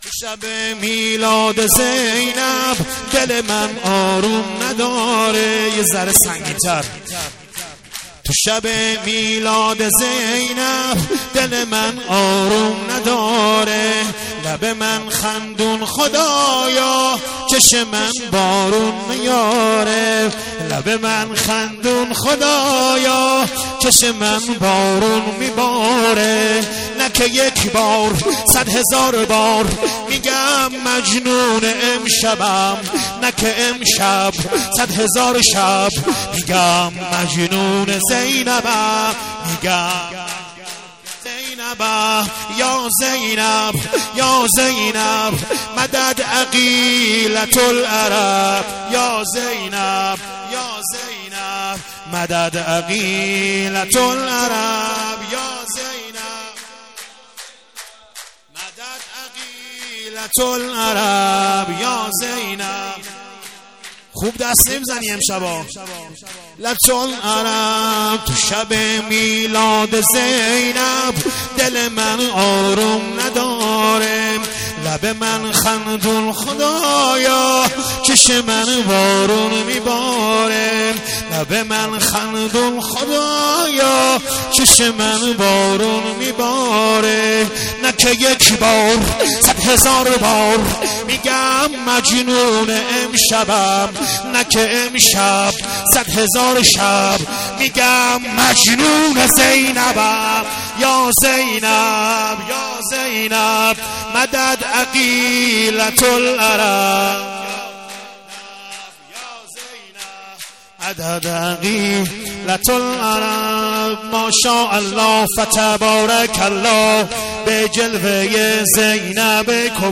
مولاتي يا زينب ...سرود...ميلاد حضرت زينب